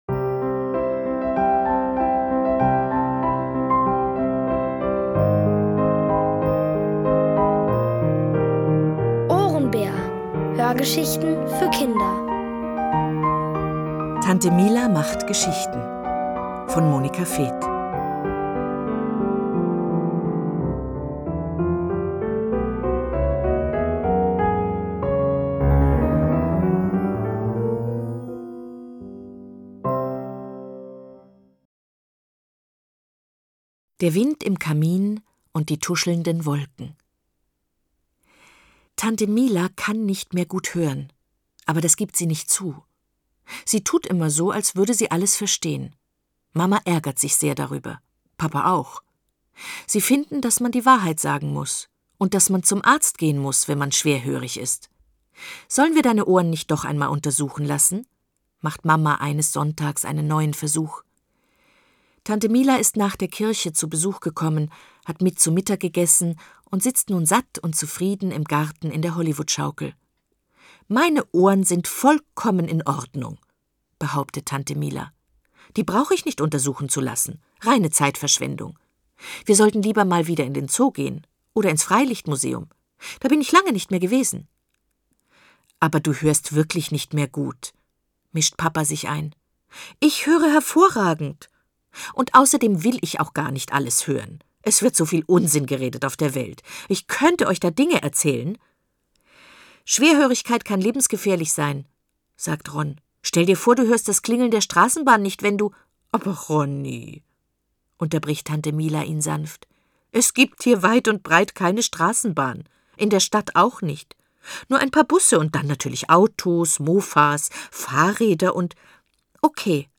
Von Autoren extra für die Reihe geschrieben und von bekannten Schauspielern gelesen.
Es liest: Eva Mattes.